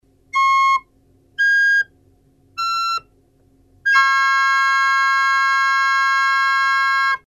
3 - Accord parfait majeur, LA4 - DO#5 - MI5. (accord "pur")
Les deux résultantes sont combinées:
La même chose, mais filtré afin de mettre plus en évidence les résultantes: